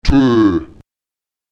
ów [¿ù] wird wie das ö in köpfen artikuliert, allerdings bedeutend länger. Es wird nie so geschlossen wie das ö in Köter ausgesprochen.
Lautsprecher tów [t¿ù] türkis